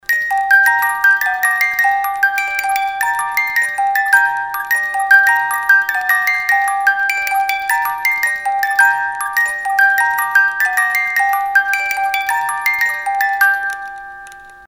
boite-a-musique.mp3